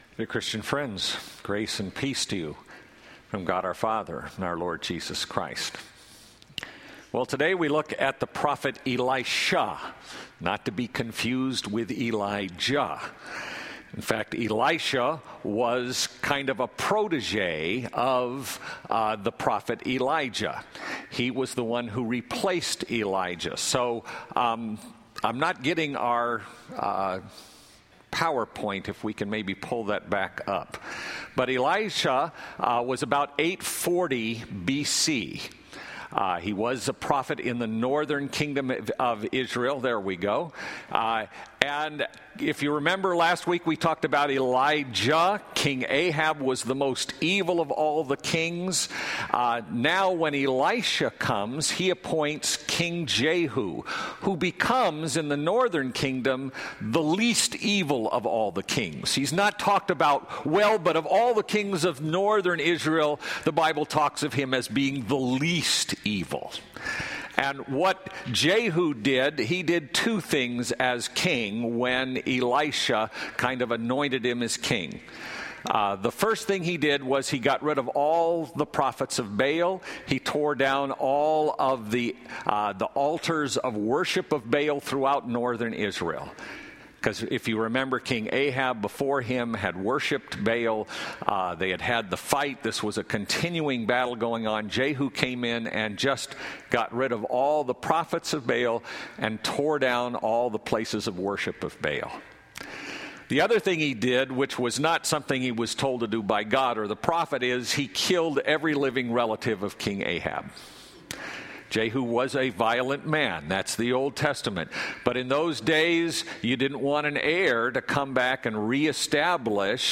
Our Redeemer Lutheran Church Garden Grove Sermons